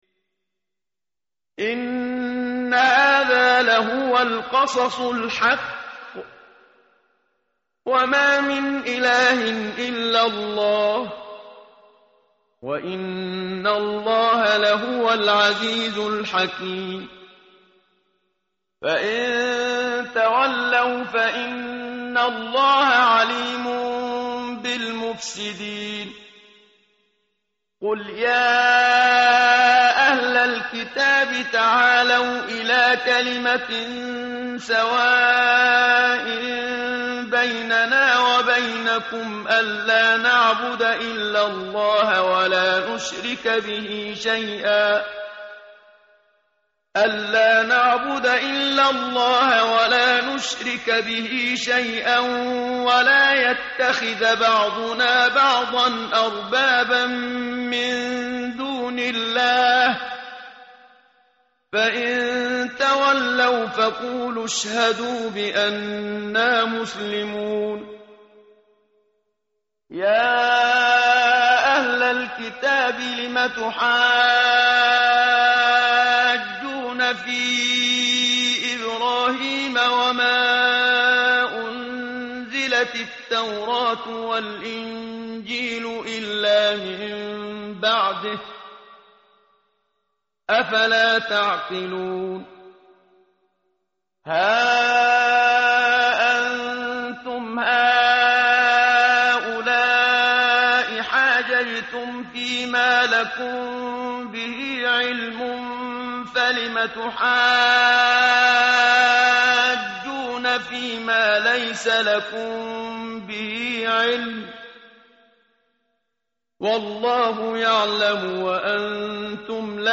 متن قرآن همراه باتلاوت قرآن و ترجمه
tartil_menshavi_page_058.mp3